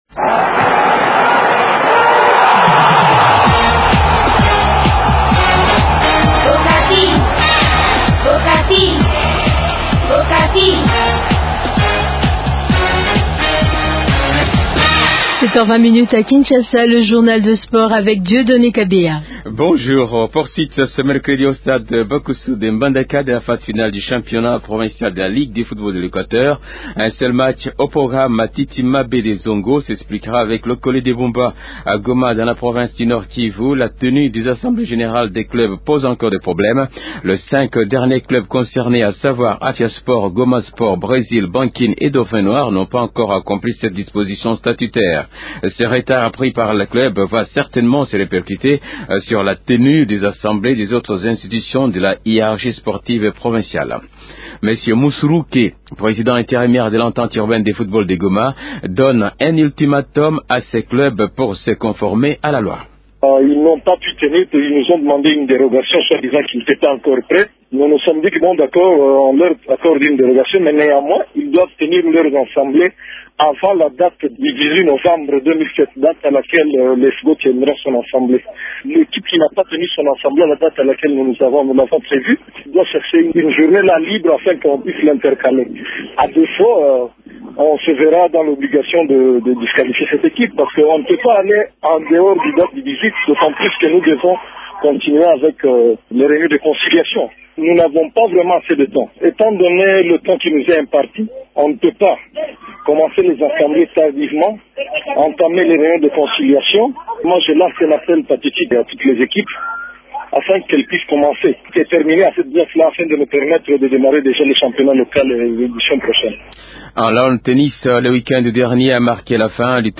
Le ministre de la Jeunesse de Sport et loisirs Pardonne Kaliba a tenu hier un point de presse au stade des Martyrs.